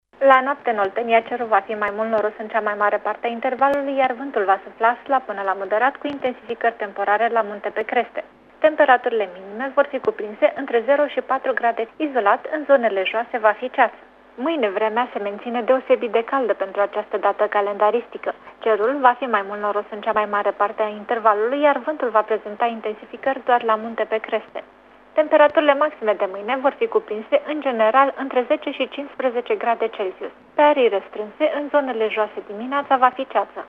Prognoza meteo 2/3 februarie (audio)